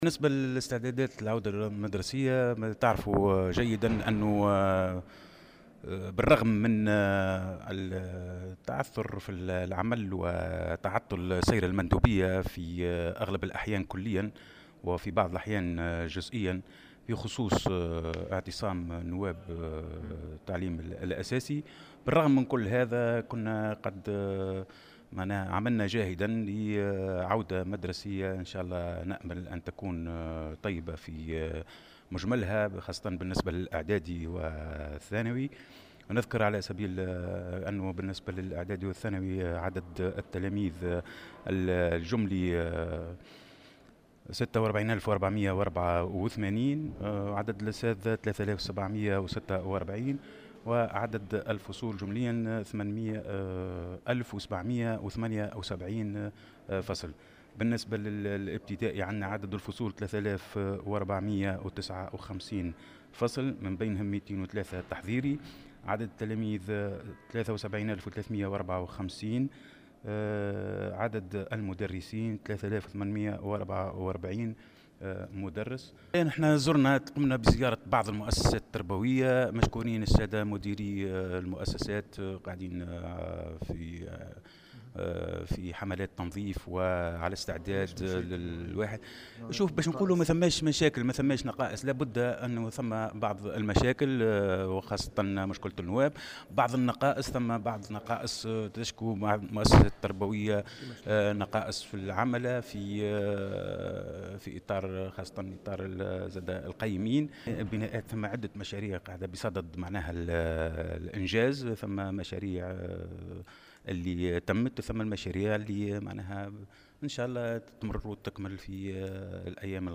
وأضاف في تصريح اليوم لمراسل "الجوهرة أف أم" أنه على الرغم من هذه المجهودات إلا أن هناك العديد من المشاكل التي تعيق العودة المدرسية من ذلك تواصل اعتصام نواب التعليم الأساسي والنقص الحاصل في عدد العملة وإطار القيمين في بعض المؤسسات التربوية.